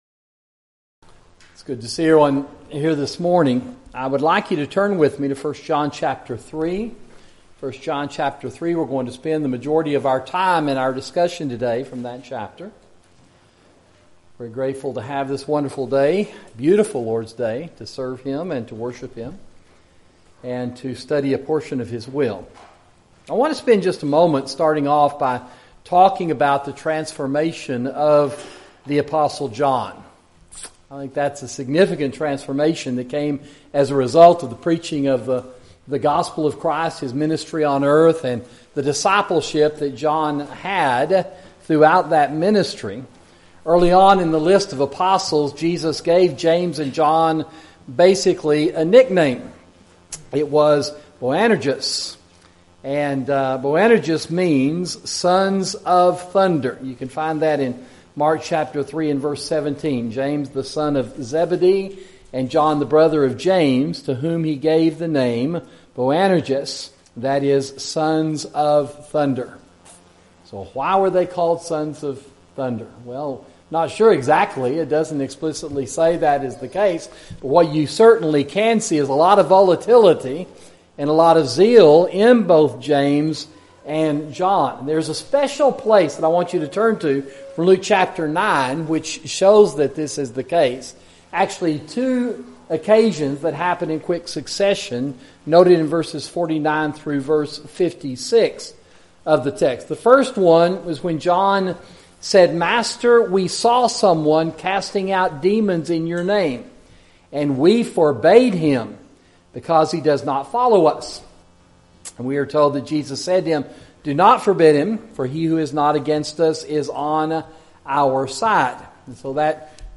Sermon: God’s Children